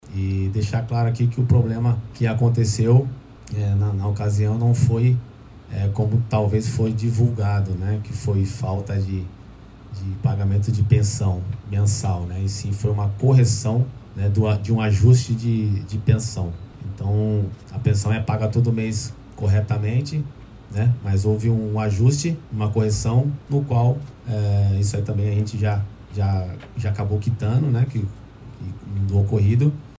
A fala se deu em coletiva de imprensa na tarde dessa quarta-feira (8):